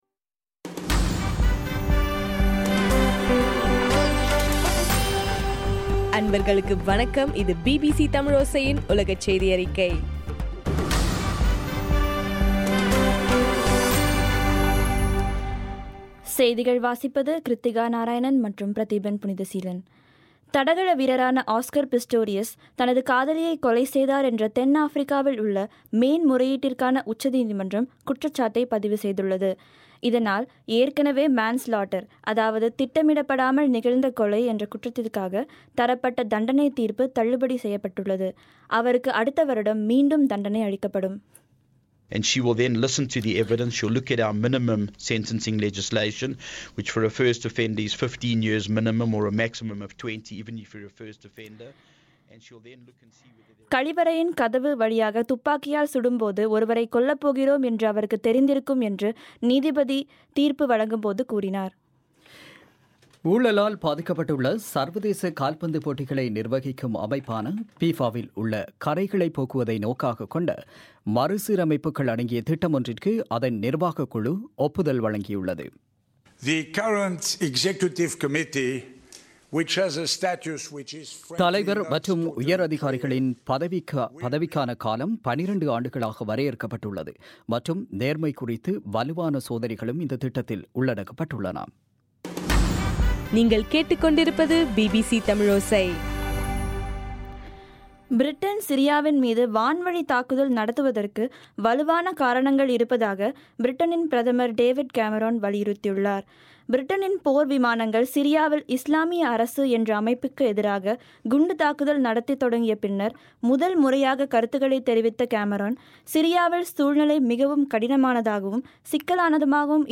டிசம்பர் 3, 2015 பிபிசி தமிழோசையின் உலகச் செய்திகள்